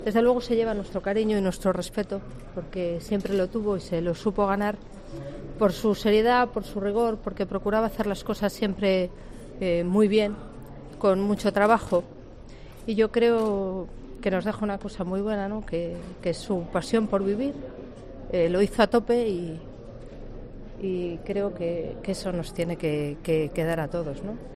Soraya Sáenz de Santamaría, vicepresidenta del Gobierno
Tras visitar la capilla ardiente con los restos de Chacón instalada en la sede del PSOE y firmar en el libro de condolencias, Sáenz de Santamaría, visiblemente emocionada, ha dedicado unas palabras a evocar a la exministra socialista.